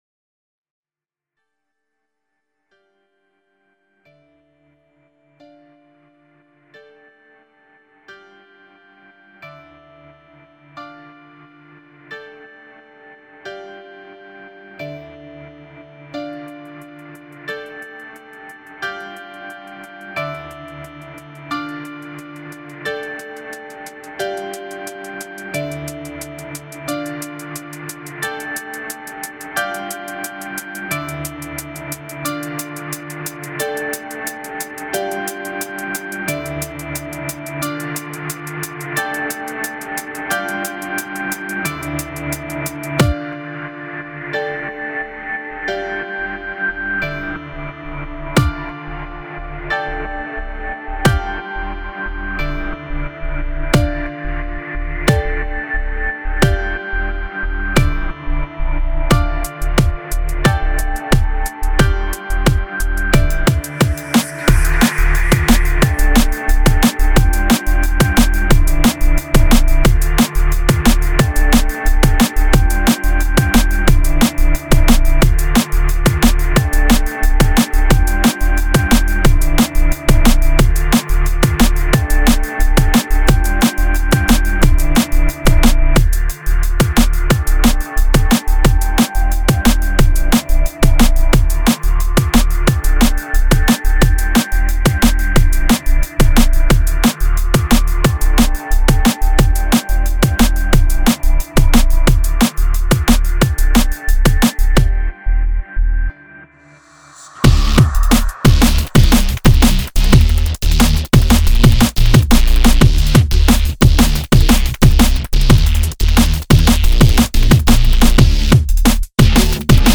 Стиль музыки: D'n'B